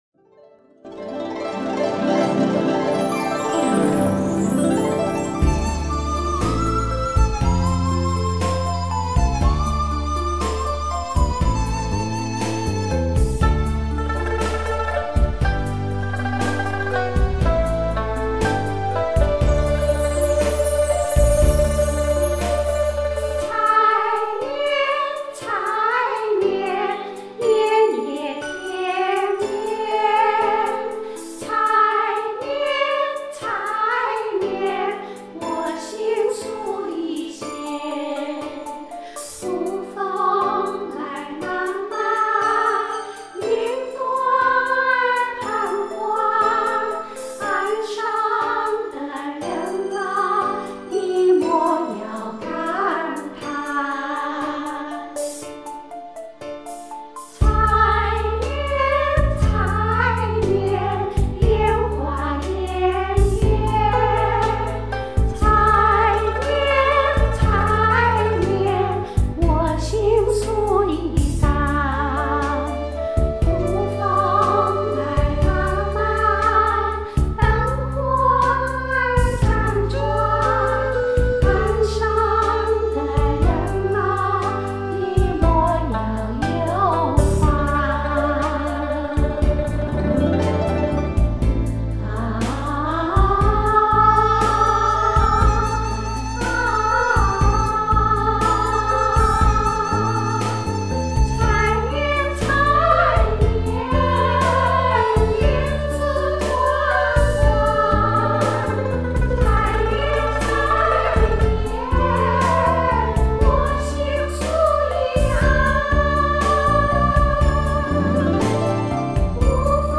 我学唱录唱这首歌好几次都不满意，就先把录了的几个Track合成一个合唱吧。